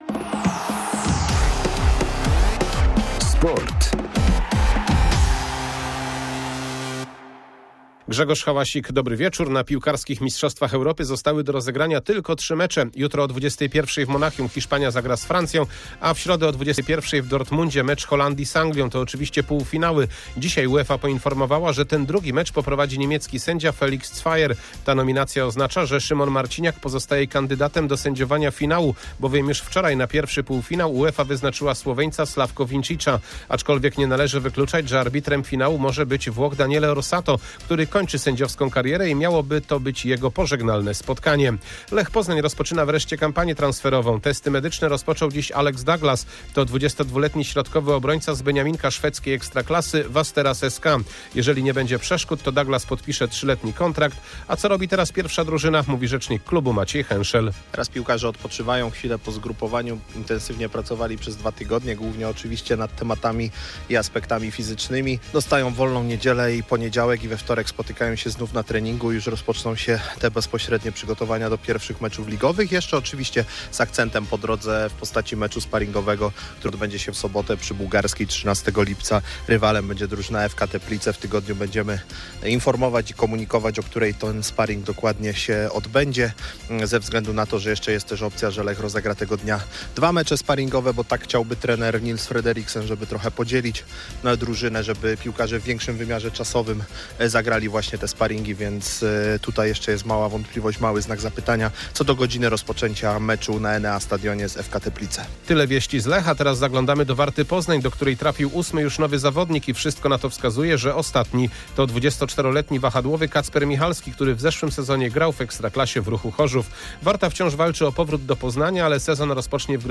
08.07. SERWIS SPORTOWY GODZ. 19:05